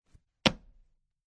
在很硬的地面上的脚步声－左声道－YS070525.mp3
通用动作/01人物/01移动状态/01硬地面/在很硬的地面上的脚步声－左声道－YS070525.mp3